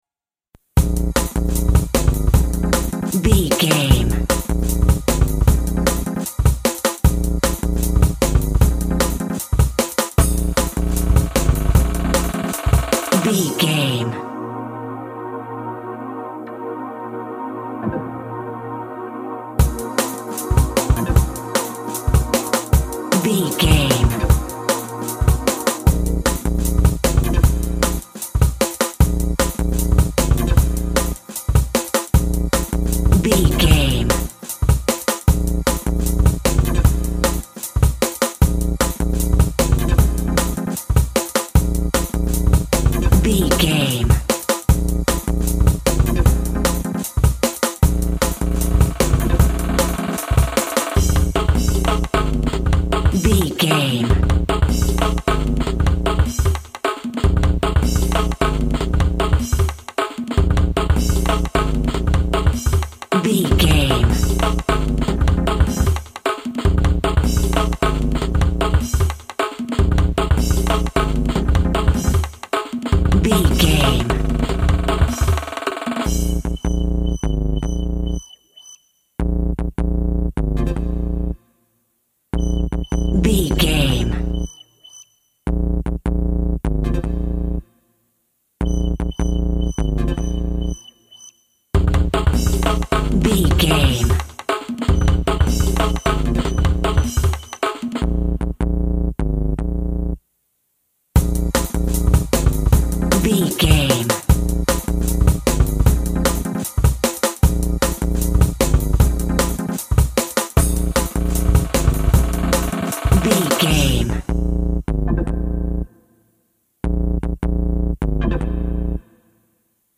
Ninetees Drum & Bass.
Epic / Action
Fast paced
Aeolian/Minor
B♭
Fast
intense
energetic
driving
dark
synthesiser
drum machine
synth lead
synth bass